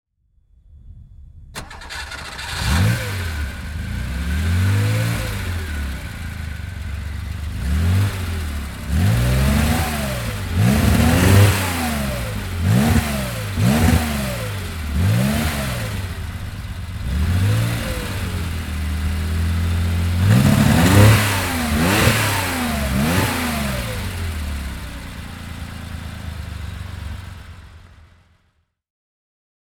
VW or Porsche 914-6 - after starting the engine there is no longer any doubt ... (Vehicle Articles)